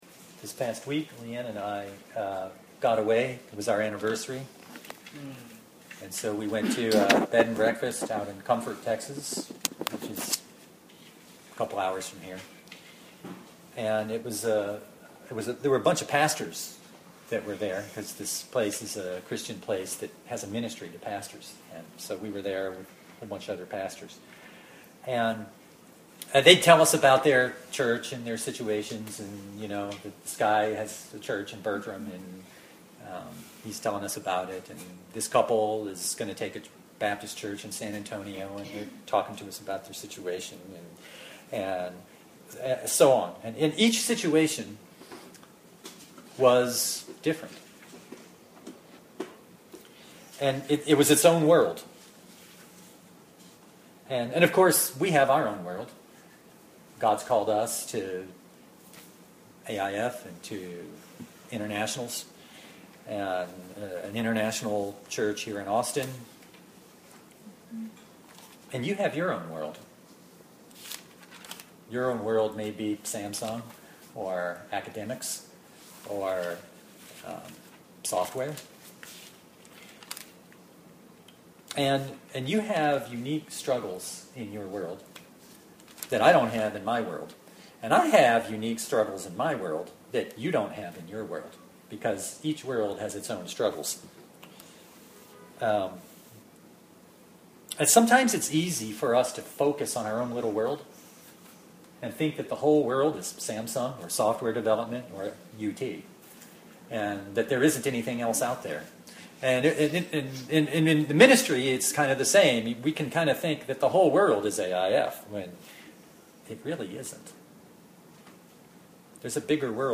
Judges 16:23-31 Service Type: Sunday Bible Text